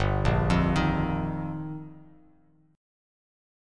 Gm7#5 chord